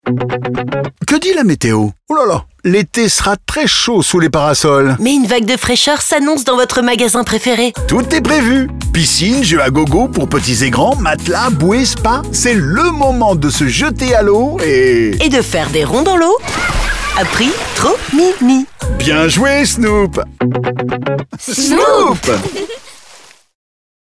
nouvelle identité sonore